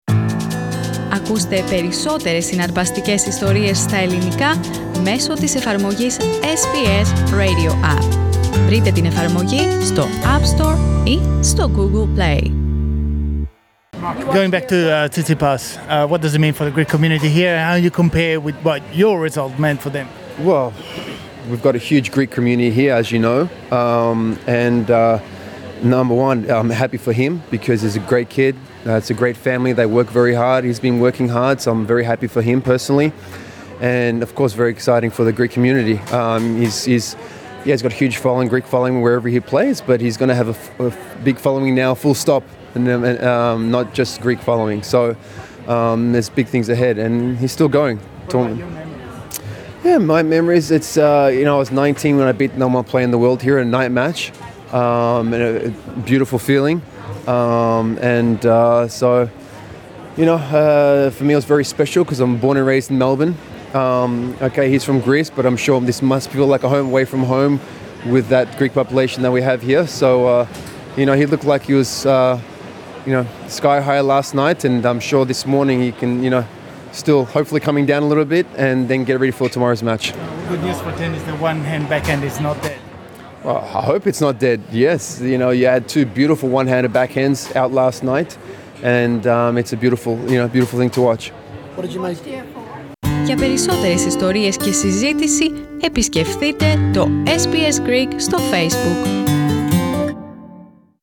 Για τη νίκη του Στέφανου Τσιτσιπά μίλησε στο SBS ο βετεράνος άσσος του τένις, Ελληνοαυστραλός Μαρκ Φιλιππούσης.